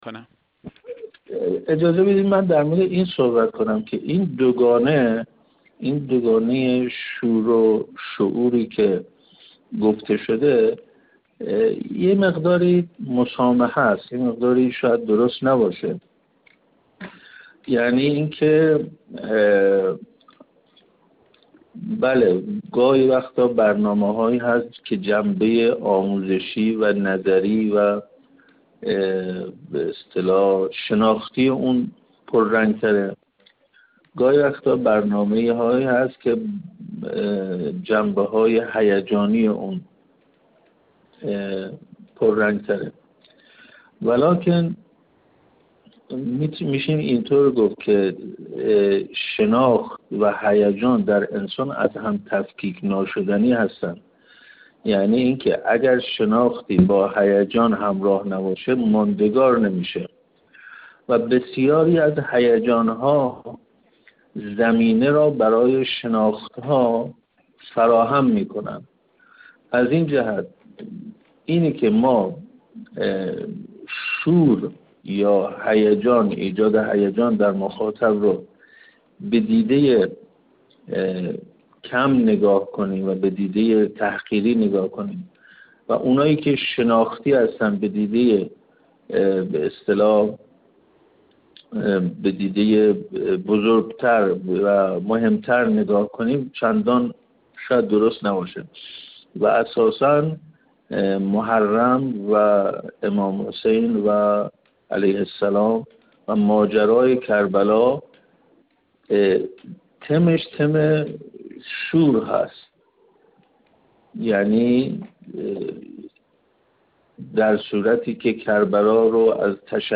گفت‌‌وگو